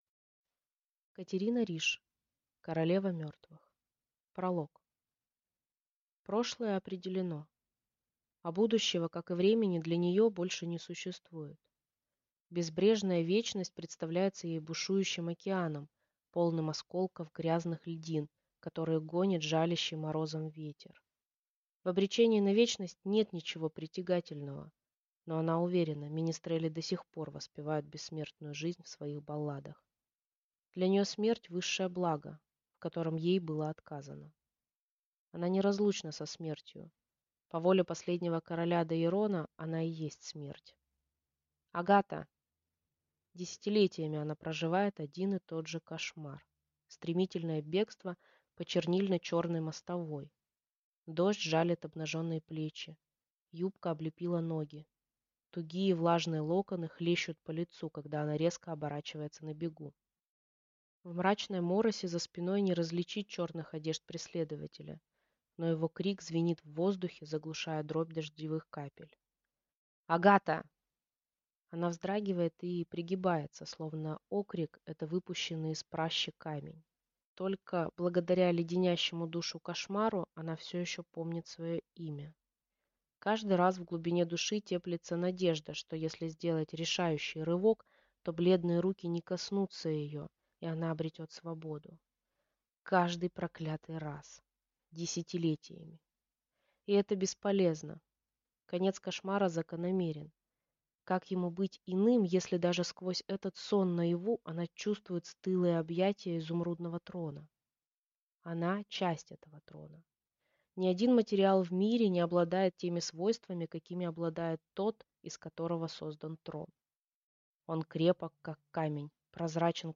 Аудиокнига Королева мертвых | Библиотека аудиокниг